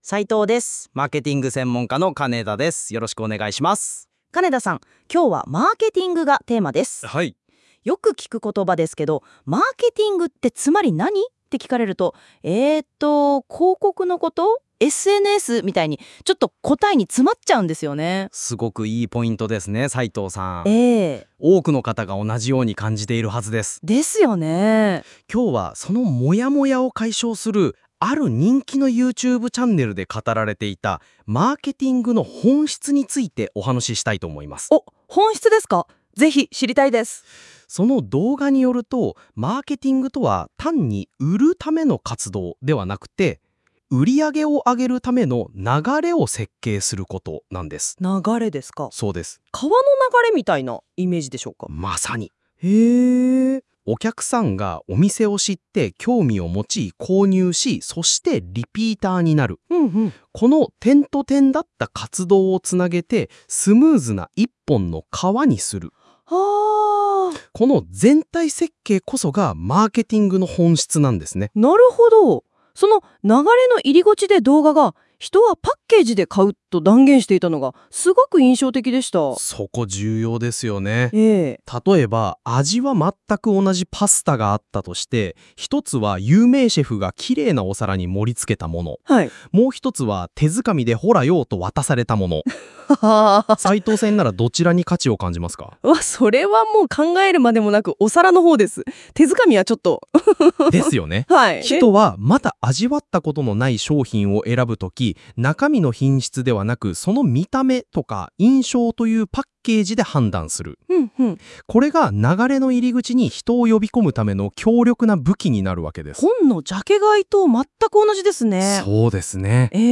会話の文字起こしはこちら↓